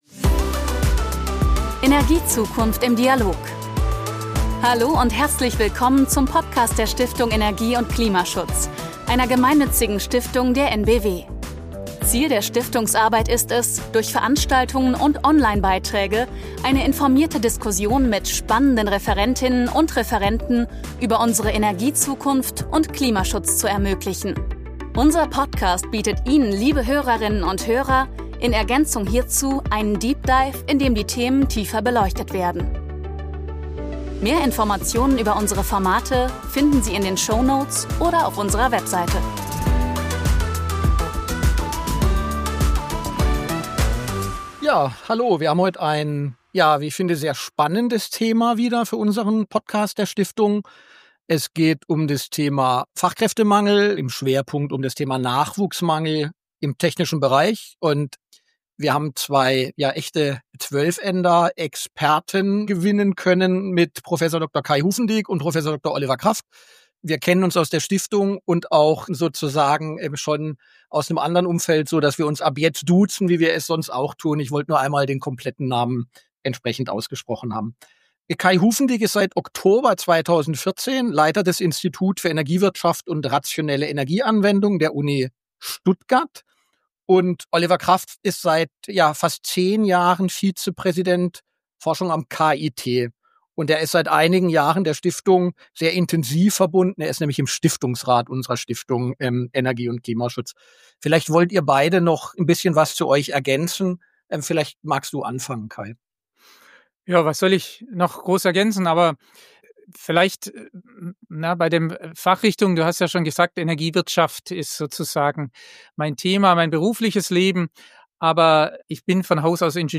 Wir sprechen mit renommierten Fachexpert*innen über Energie und Klimaschutz. Jede Podcast-Folge fokussiert ein Schwerpunkt-Thema aus den Bereichen Energie, Technik, Energiewende, Klimaschutz u.v.m. Eine neue Podcast-Folge erscheint i.d.R. jeden zweiten Donnerstag im Monat.